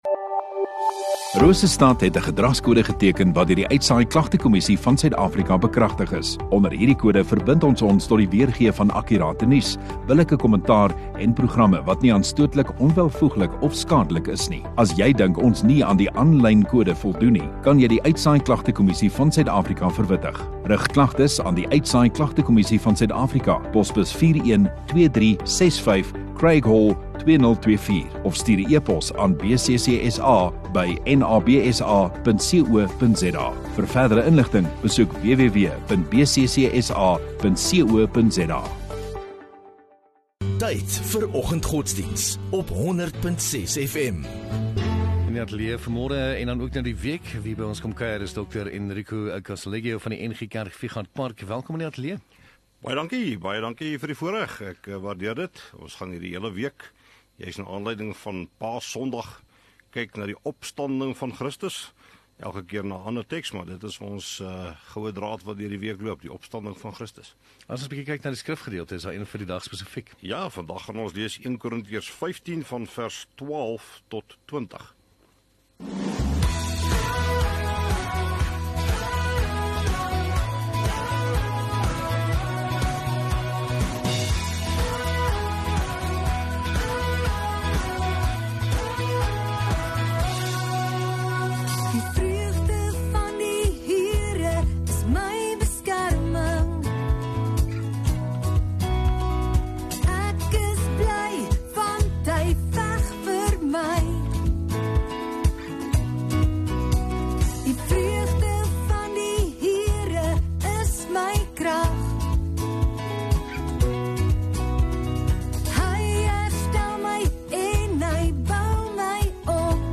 1 Apr Maandag Oggenddiens